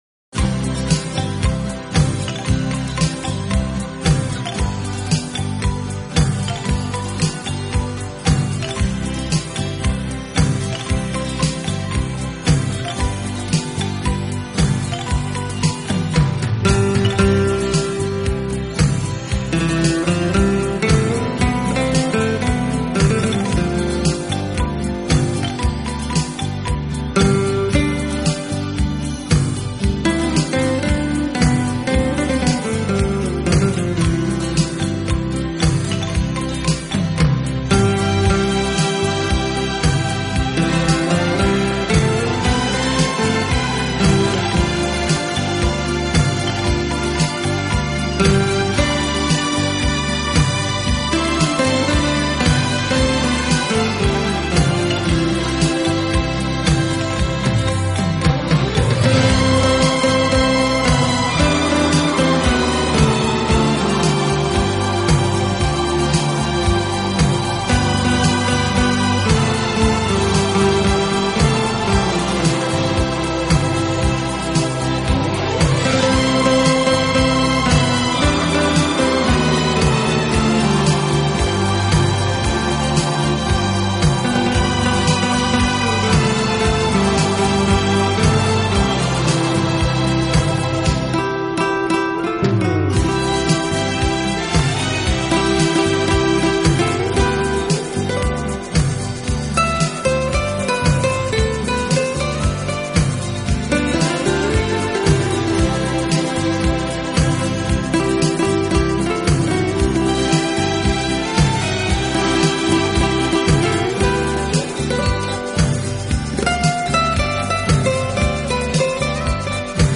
音乐流派：Instrumental / New Age
他和他的管弦乐队组成的乐队以吉他为主，成功地将吉他和管弦乐相融合在一起，以